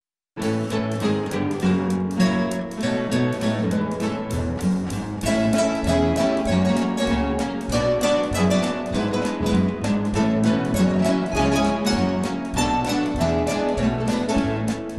Musik für Gitarrenorchester